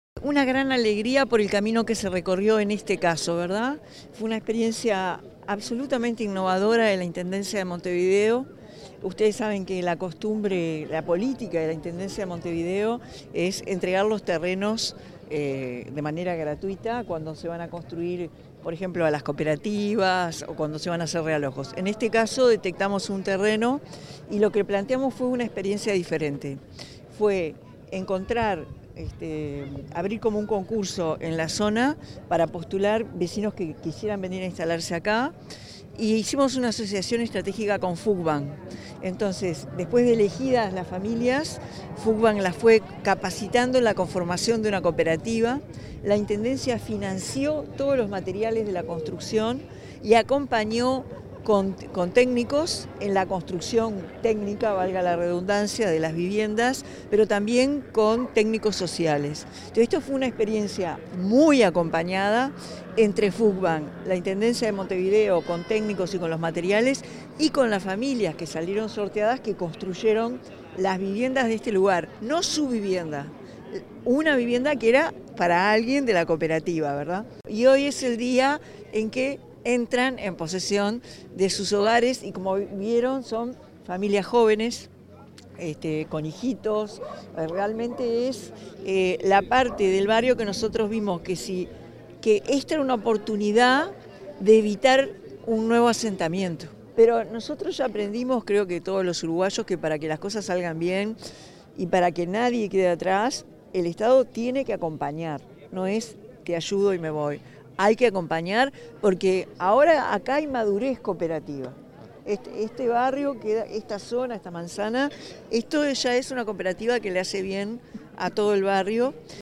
Declaraciones de la presidenta de la República en ejercicio, Carolina Cosse, en inauguración de viviendas
Declaraciones de la presidenta de la República en ejercicio, Carolina Cosse, en inauguración de viviendas 05/07/2025 Compartir Facebook X Copiar enlace WhatsApp LinkedIn Tras la entrega de las llaves de 28 viviendas construidas en formato cooperativo en el barrio Piedras Blancas, de Montevideo, la presidenta de la República en ejercicio, Carolina Cosse, efectuó declaraciones a la prensa.